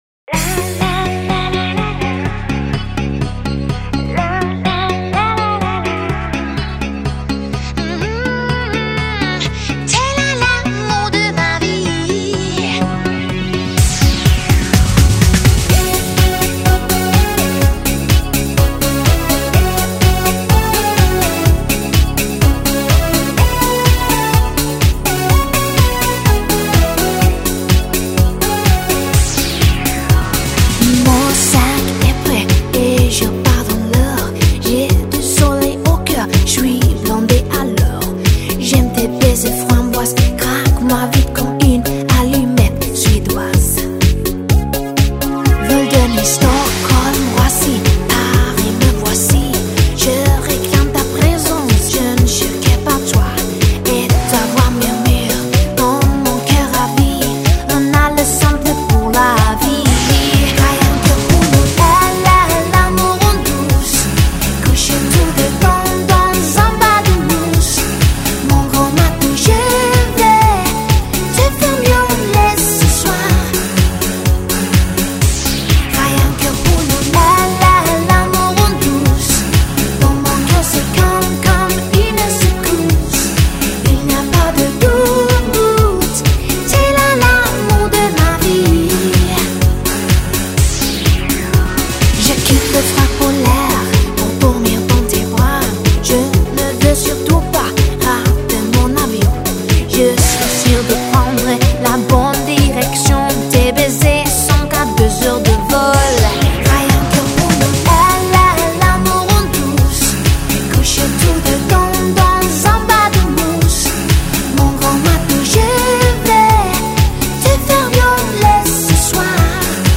一位来自瑞典的美女Pod Dance歌手